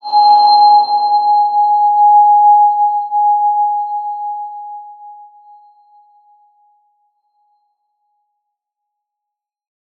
X_BasicBells-G#3-mf.wav